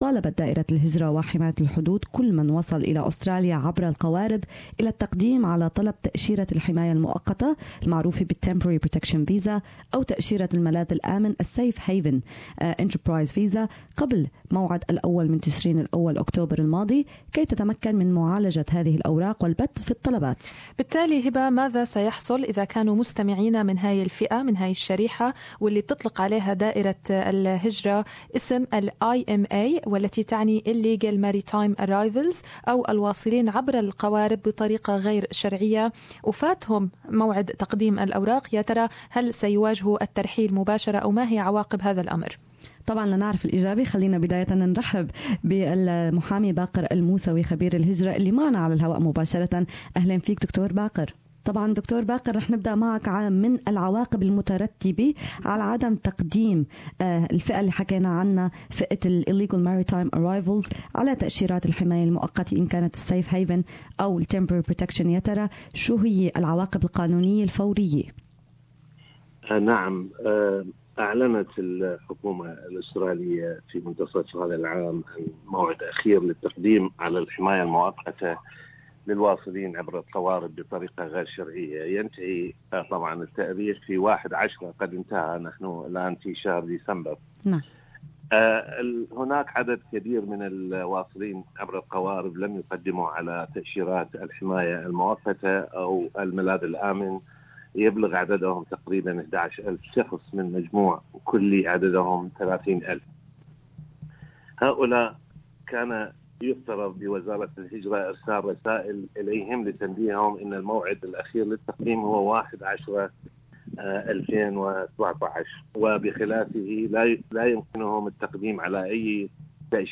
لمعرفة الاجابة عن هذا السؤال وغيرها من الاسئلة حول وضع هذه الفئة استضاف برنامج البيت بيتك = خبير الهجرة واللجوء